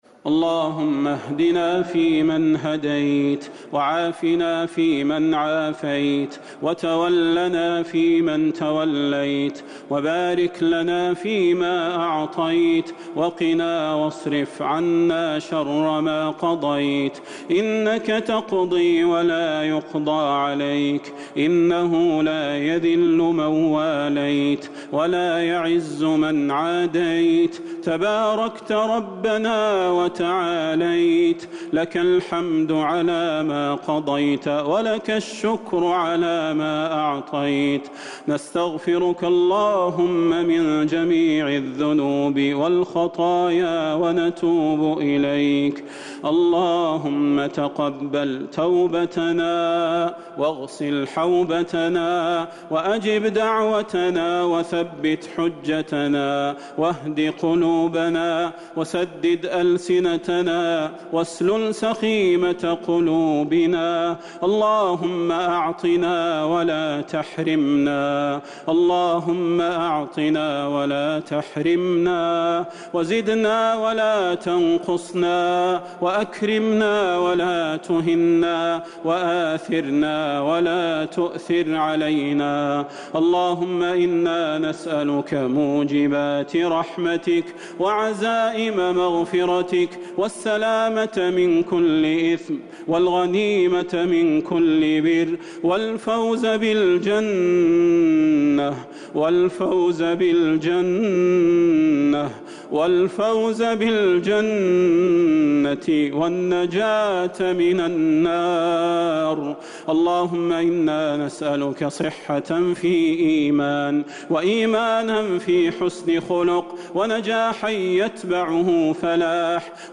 دعاء القنوت ليلة 4 رمضان 1441هـ > تراويح الحرم النبوي عام 1441 🕌 > التراويح - تلاوات الحرمين